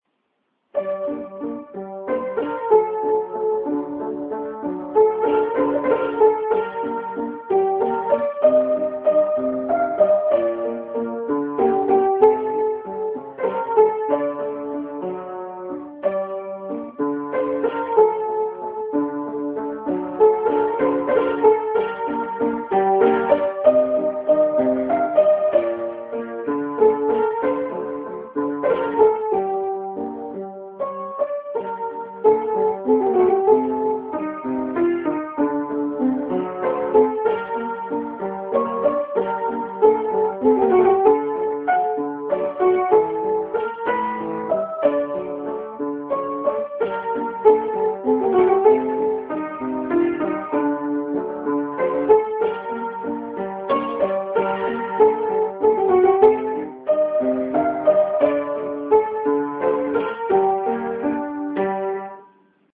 Мелодия воспроизведена по нотному изданию 1913 года